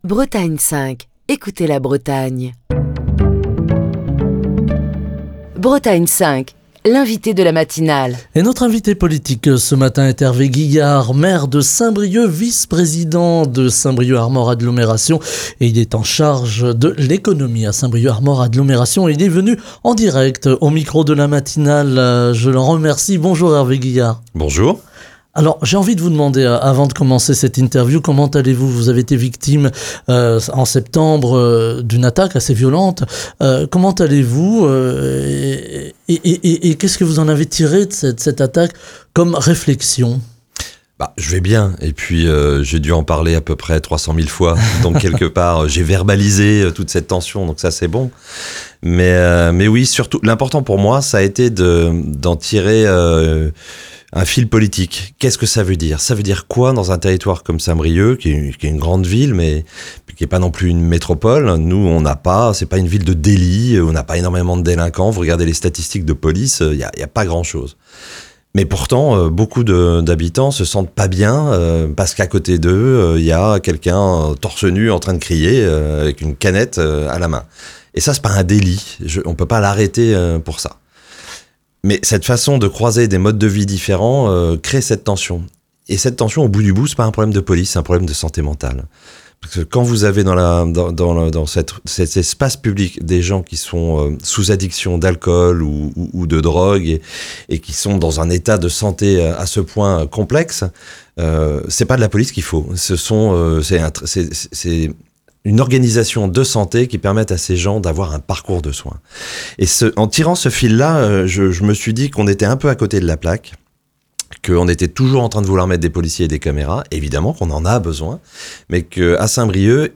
Ce matin, Hervé Guihard, maire de Saint-Brieuc, vice-président de Saint-Brieuc Armor Agglomération, en charge de l'Économie, est l'invité de la matinale de Bretagne 5. Hervé Guihard, membre de Place Publique, explique comment l'association des habitants d'une ville telle que Saint-Brieuc est nécessaire dans la construction de projets à long terme, pour préparer l'a